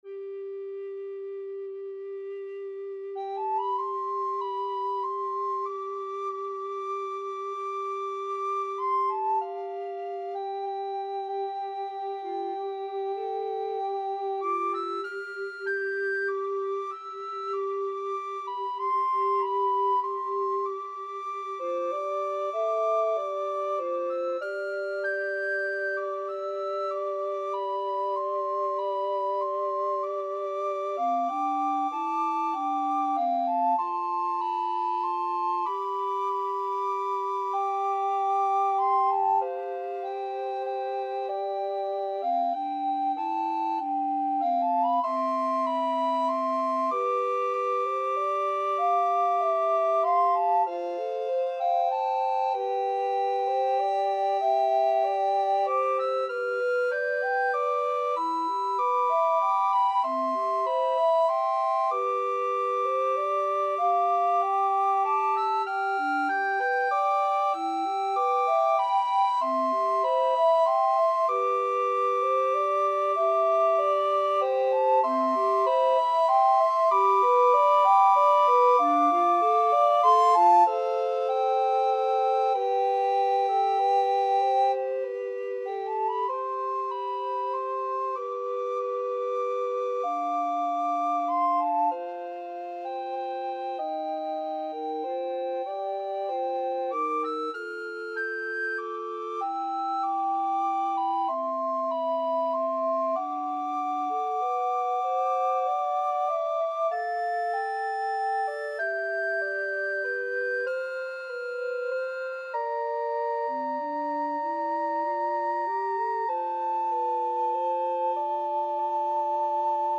Free Sheet music for Recorder Trio
Soprano RecorderAlto RecorderTenor Recorder
3/4 (View more 3/4 Music)
C major (Sounding Pitch) (View more C major Music for Recorder Trio )
Andante = c. 96
Traditional (View more Traditional Recorder Trio Music)